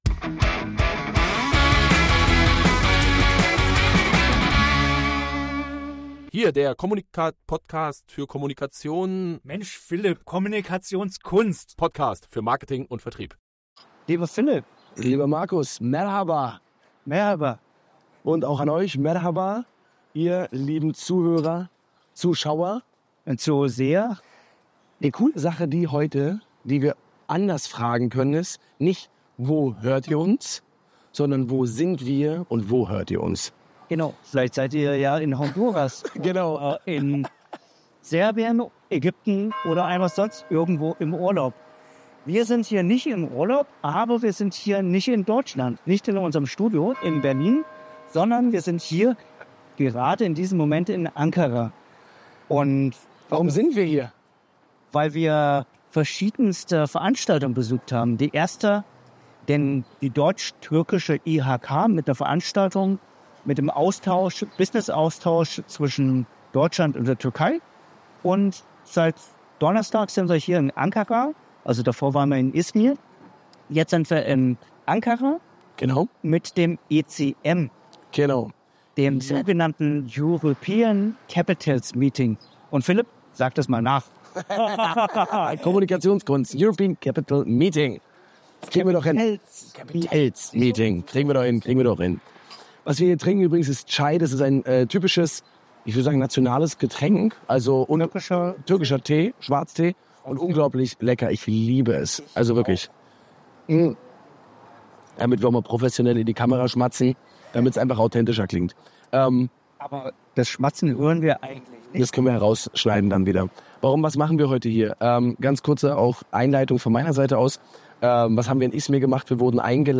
In dieser besonderen On-the-Road-Folge nehmen wir euch mit auf unsere Reise in die Türkei!